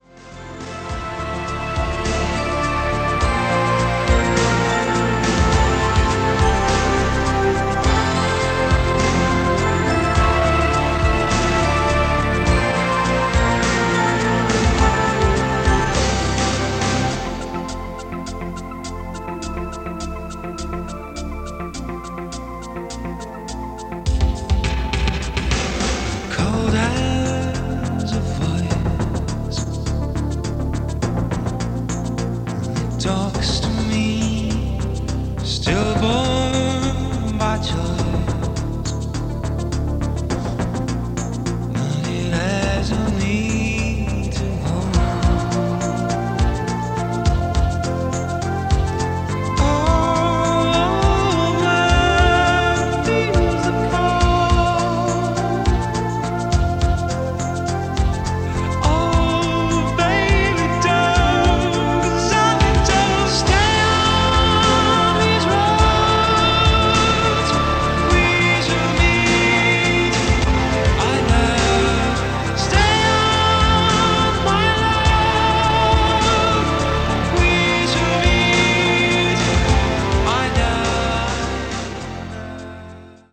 Below is a test recording made with the KX-W321 on a normal position tape and played back by it:
Yamaha-KX-W321-Test-Recording.mp3